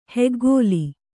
♪ heggōli